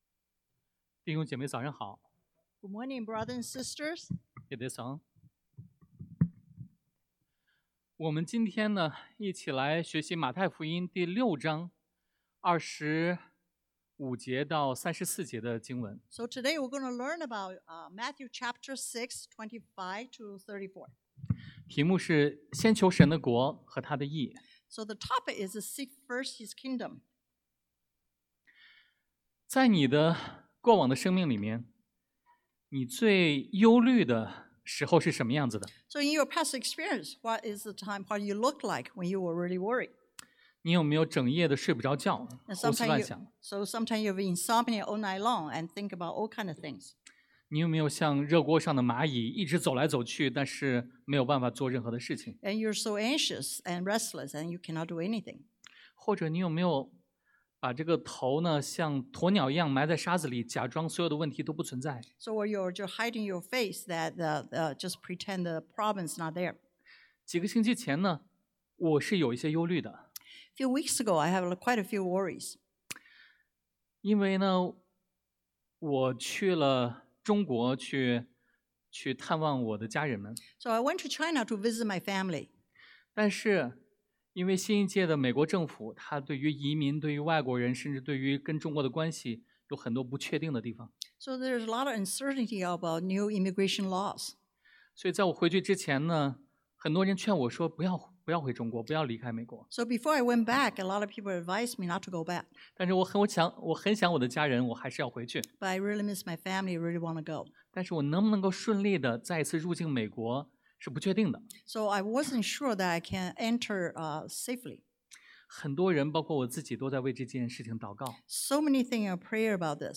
Passage: Matthew马太福音 6:25-34 Service Type: Sunday AM